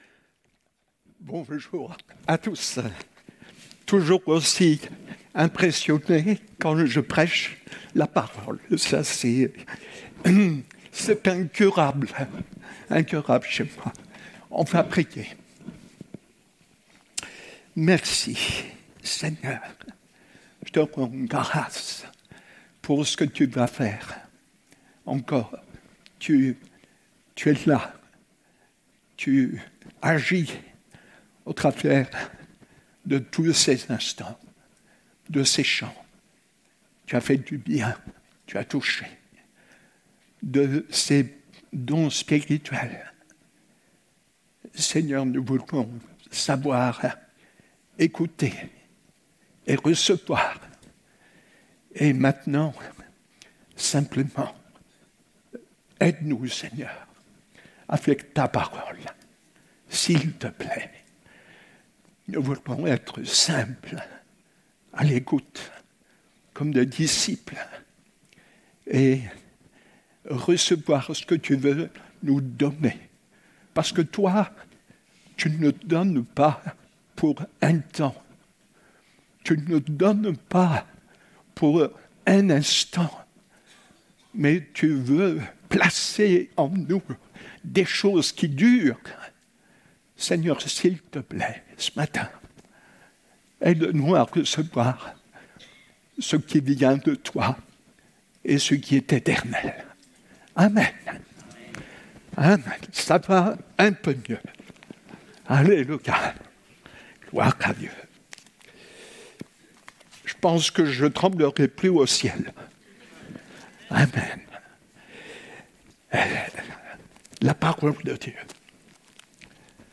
Découvrez en replay vidéo le message apporté à l'Eglise Ciel Ouvert
Matthieu 25:6 Réunion: Culte Célébration du dimanche 6 octobre 2019